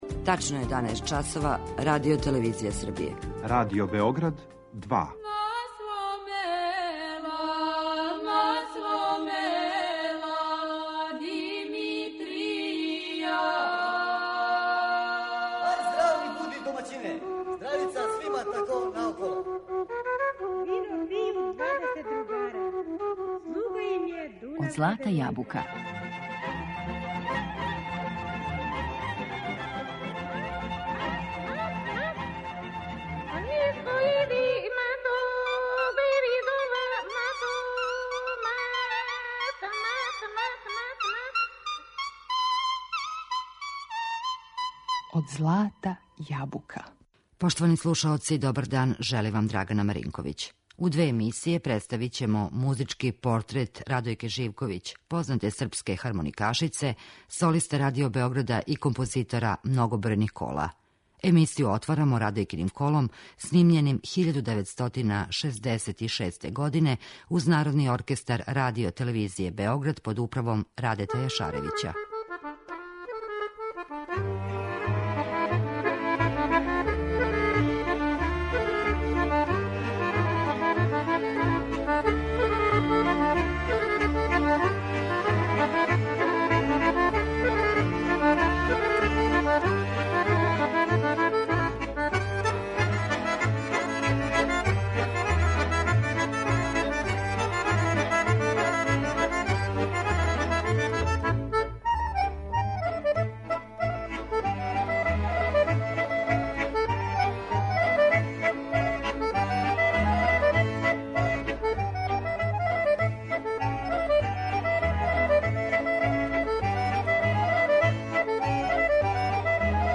Емисија је резервисана за изворну народну музику.